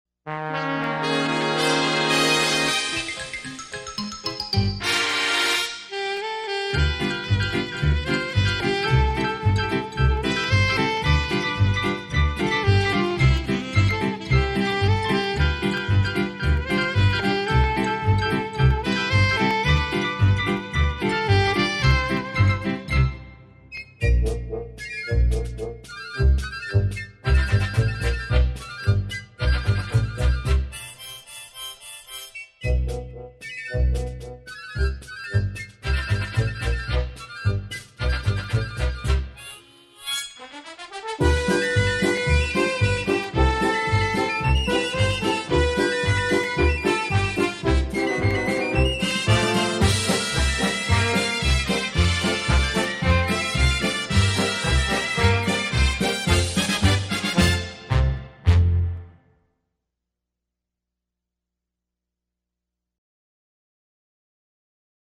Here's Another Appropiate Tune From The Speaker of A 1941 FADA "Streamliner"